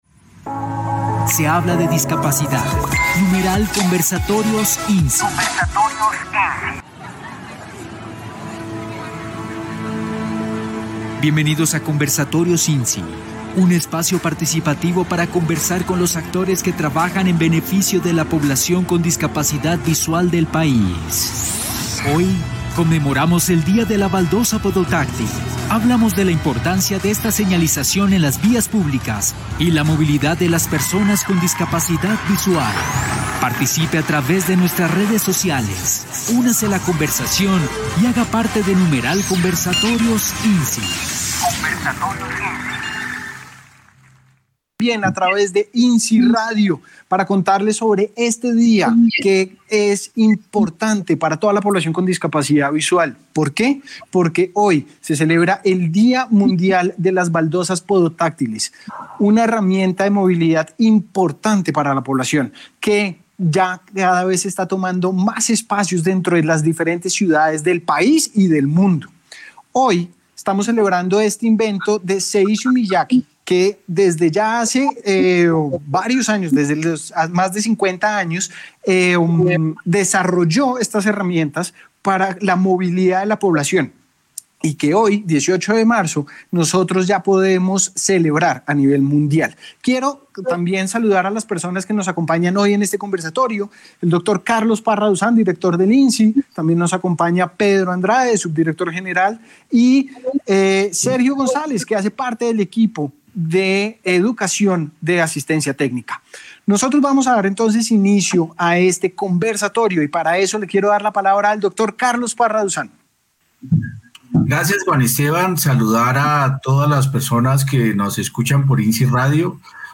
conversatorio_18 marzo 2021_baldosas podotáctiles | Instituto Nacional para Ciegos
4478 EVENTO_CONVERSATORIO_COMPLETO_18_MARZO_2021_BALDOSAS_PODOTACTILES.mp3